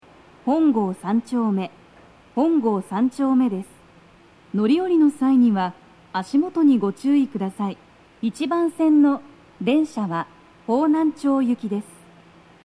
スピーカー種類 BOSE天井型
足元注意喚起放送が付帯されており、粘りが必要です。
1番線 荻窪・方南町方面 到着放送 【女声